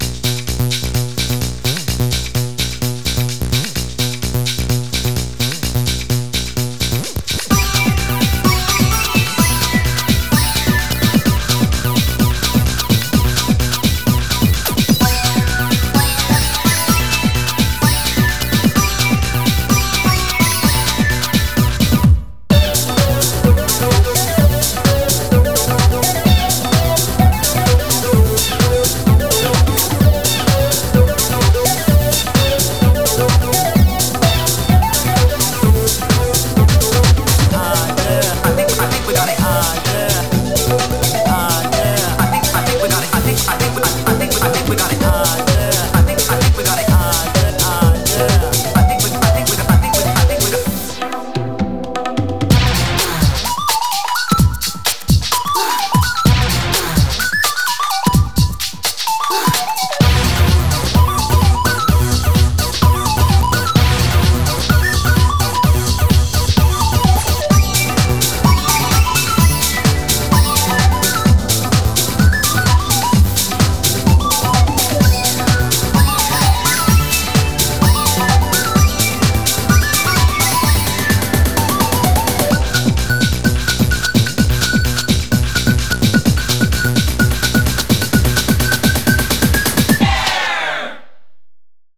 BPM128
Better quality audio.